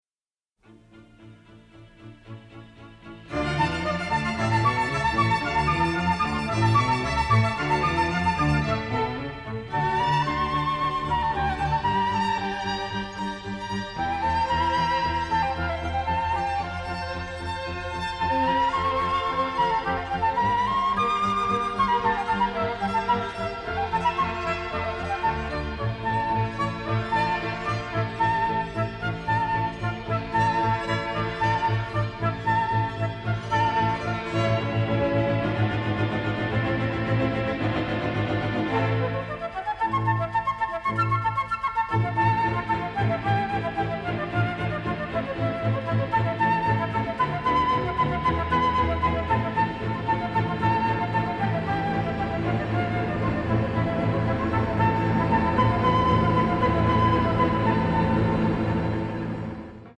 Romantic and melancholic